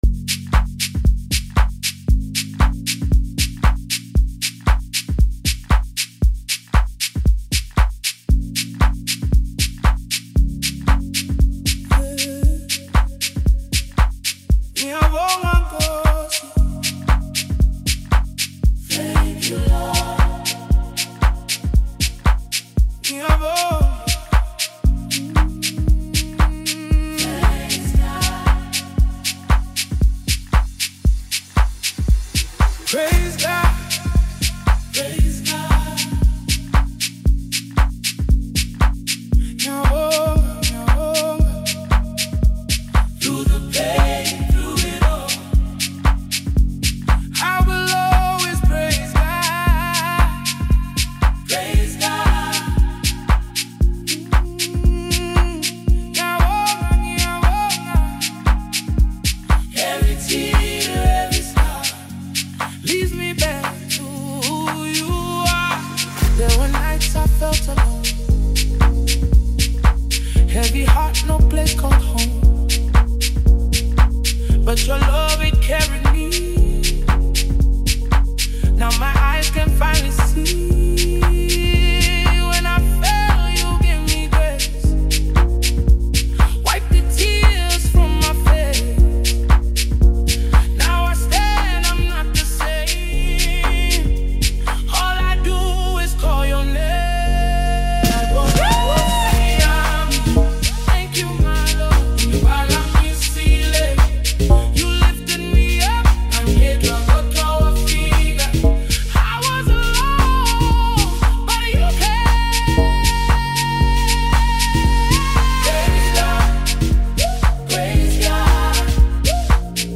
Home » South African Music
and exceptional vocal delivery for devoted listeners.